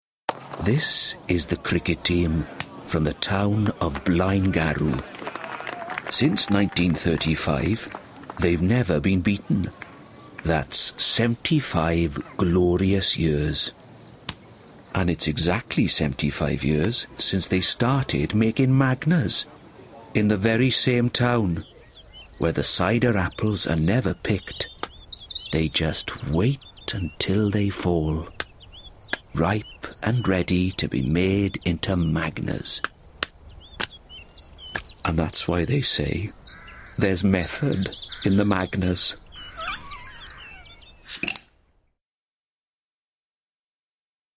Commercial - Magners - Inviting, Down to Earth, Relaxing